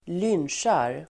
Ladda ner uttalet
Uttal: [²l'yn:sjar]